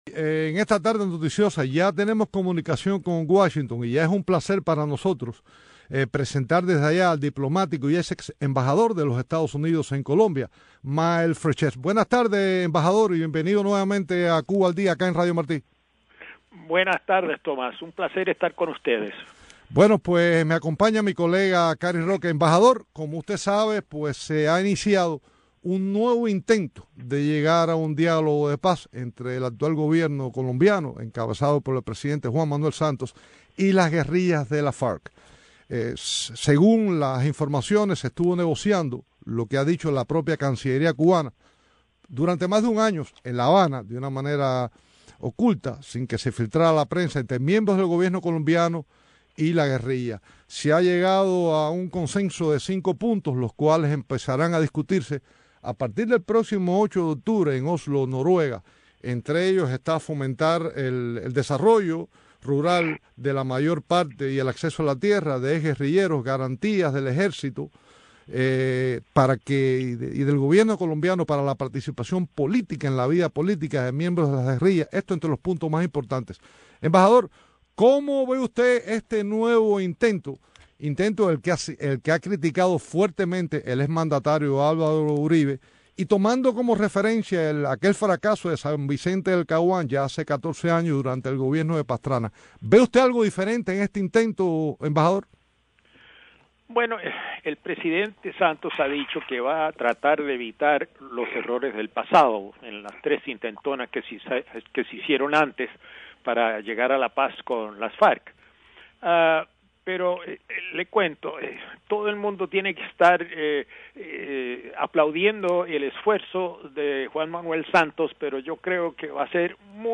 Declaraciones de MylesFrechette en el programa radial Cuba al Día